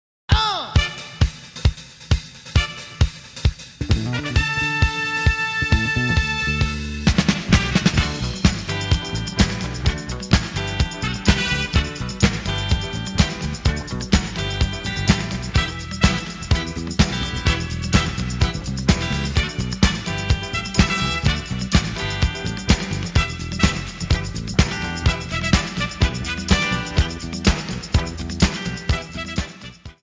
Backing track files: 1970s (954)
(fade out)